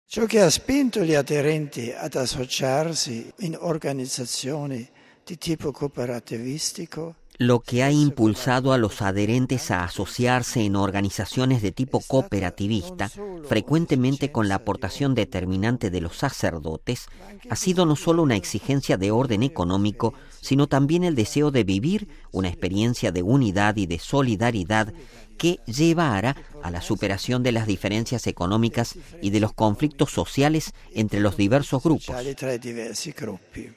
A mediodía, en la Sala Clementina del Palacio Apostólico del Vaticano, Benedicto XVI celebró un encuentro con una Delegación de la Confederación de las Cooperativas Italianas y de los Bancos de Crédito Cooperativo, en total unas 80 personas.